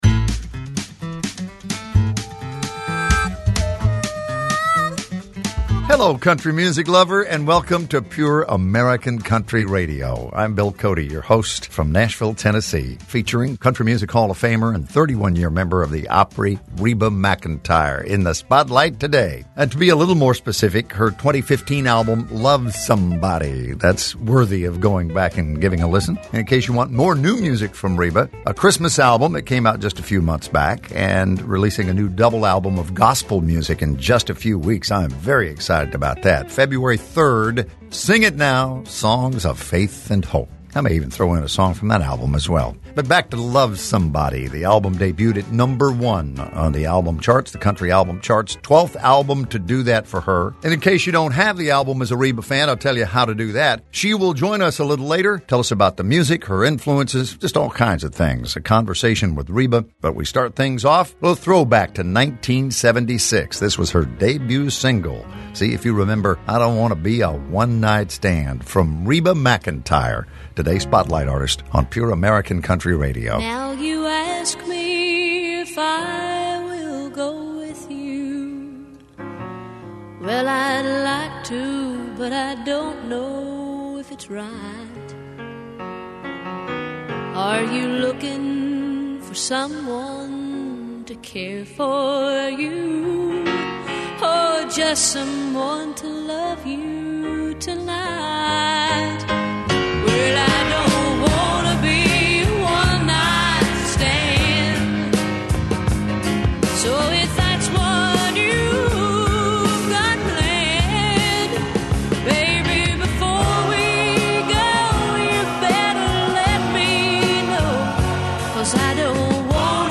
America loves classic country music! And THE PURE AMERICAN COUNTRY RADIO SHOW delivers it in two stand-alone weekend hours.
Pure_American_Country_demo.mp3